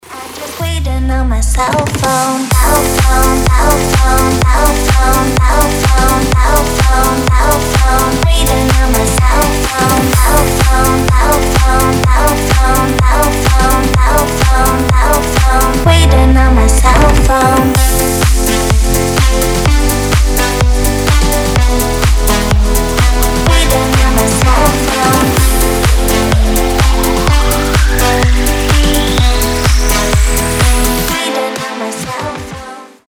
• Качество: 320, Stereo
громкие
house